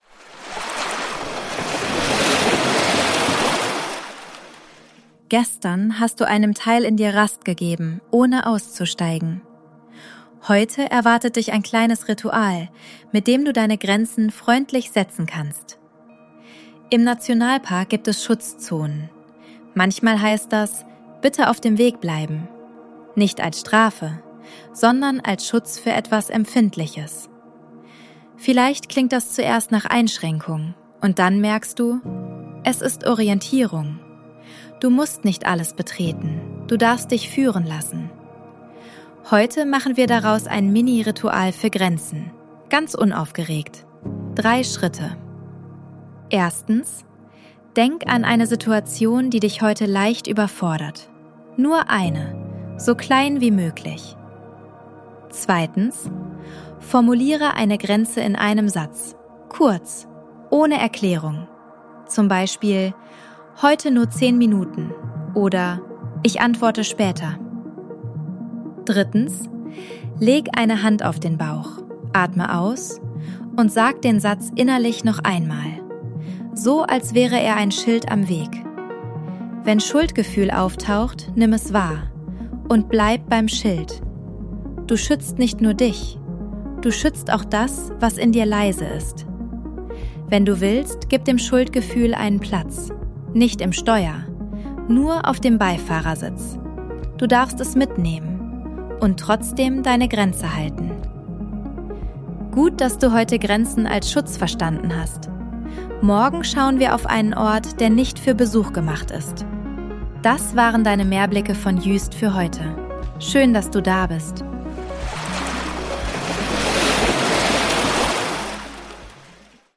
Sounds & Mix: ElevenLabs und eigene Atmos